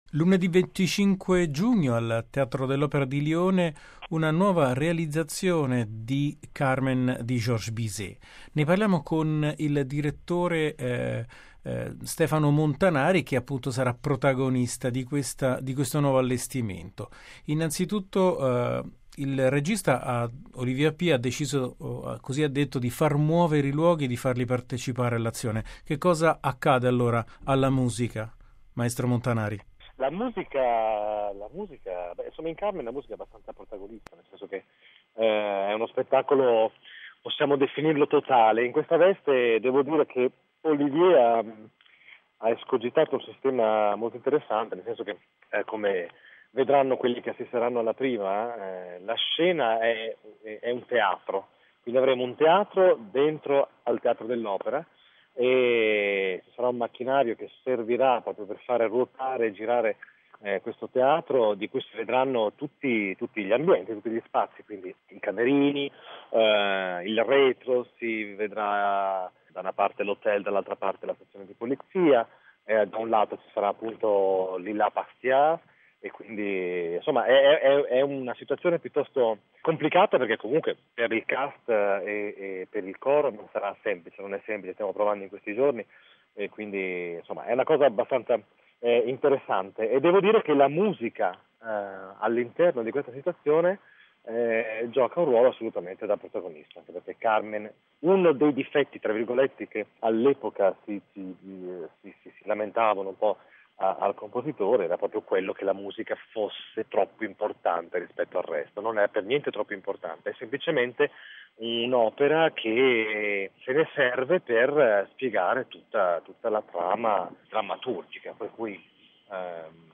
Qui sotto l'intervista in onda su Zoom, notizie dal pianeta musica